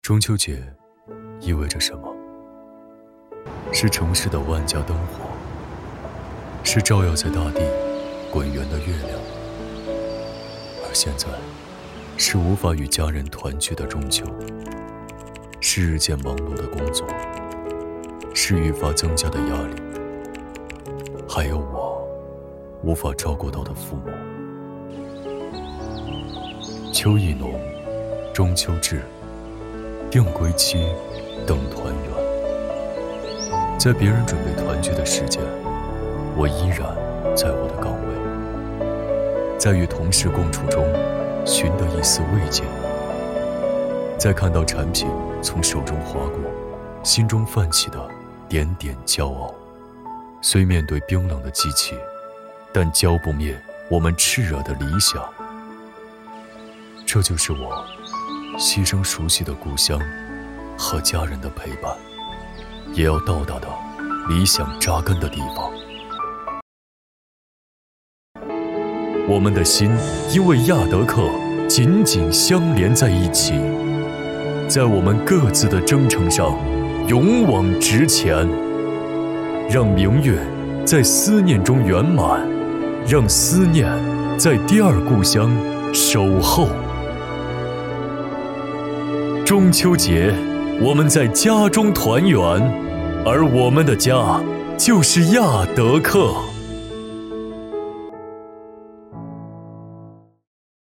男女声旁白配音_微电影旁白
男346--微电影-亚德客中秋暖心短片-.mp3